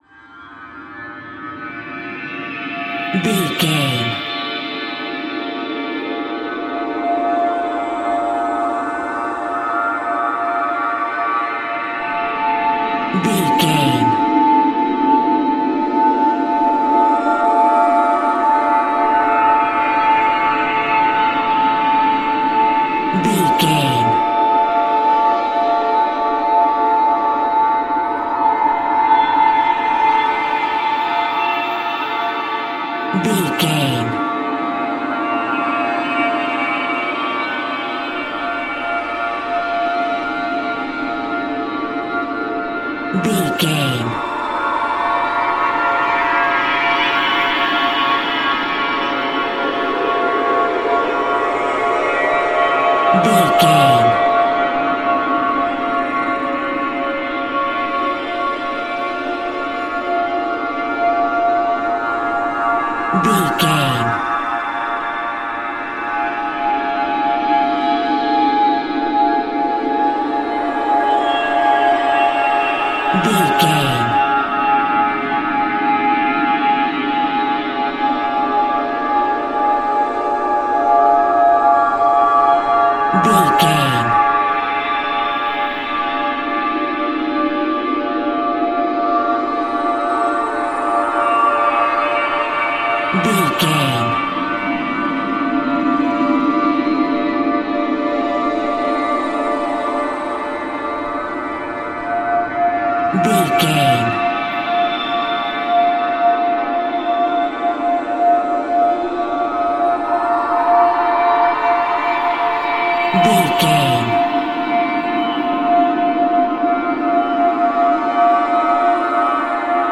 Sun Atmosphere.
In-crescendo
Thriller
Aeolian/Minor
scary
tension
ominous
dark
suspense
eerie
strings
horror
synth
ambience
pads